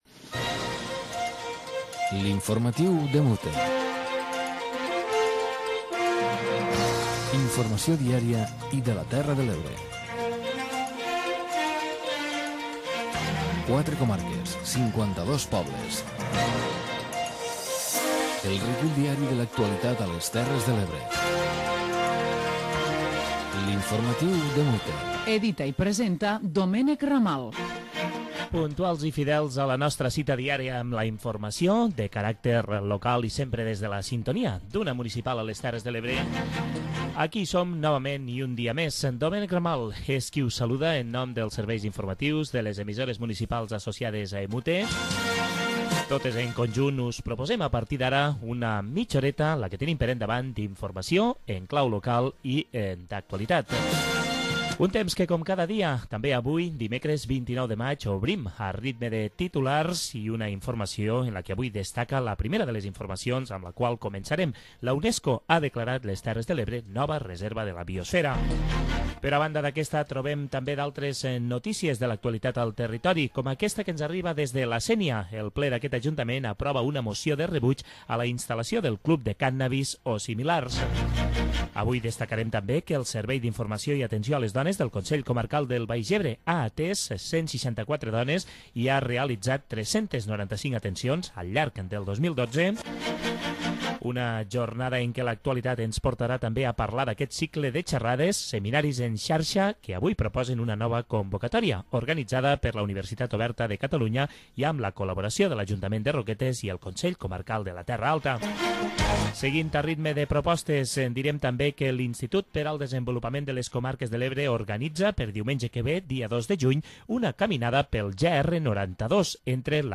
Informatiu comarcal diari de les emissores municipals de les Terres de l'Ebre.